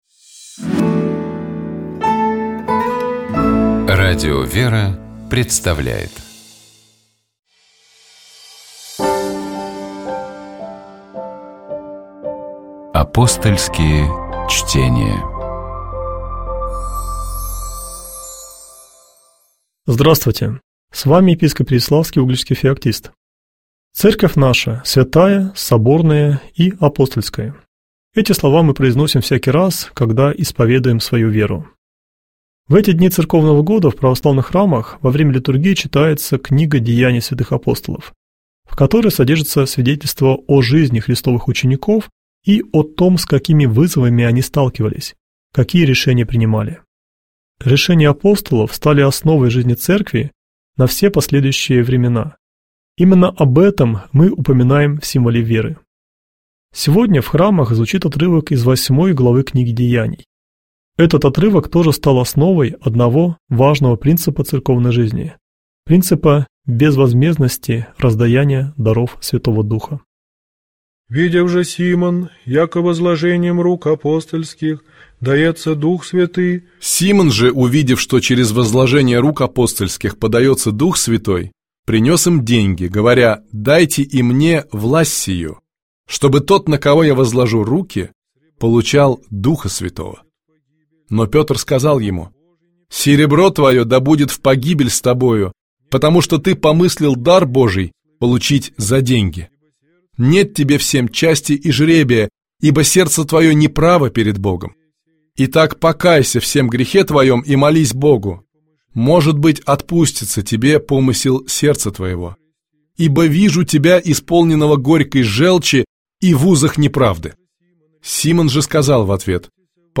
Псалом 54. Богослужебные чтения - Радио ВЕРА